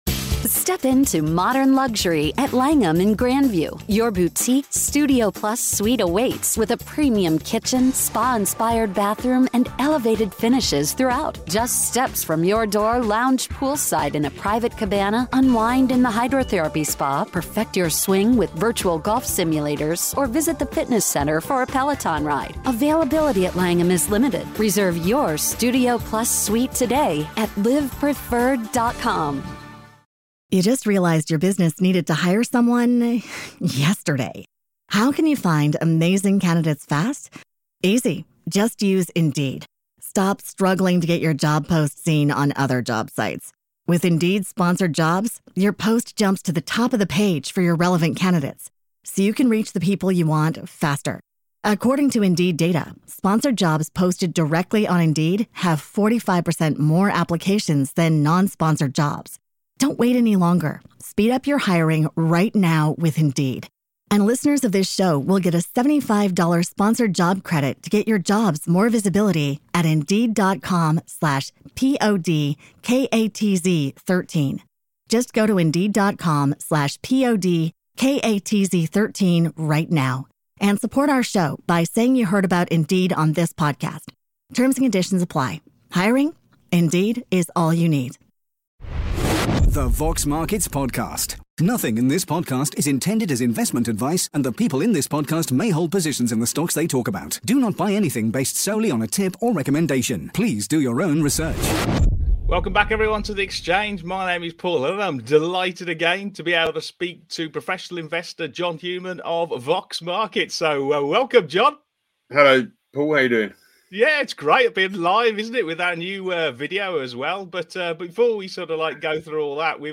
On today's live Exchange show